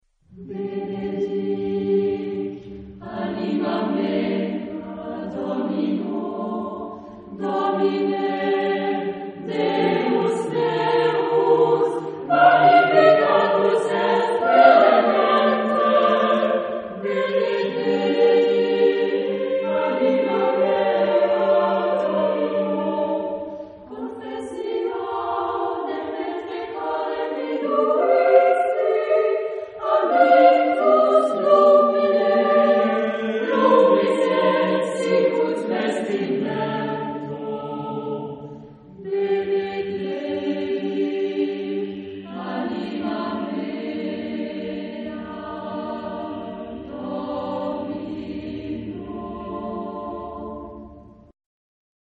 Epoque: 20th century  (1950-1999)
Genre-Style-Form: Sacred ; Psalm
Type of Choir: SATB  (4 mixed voices )
Tonality: C major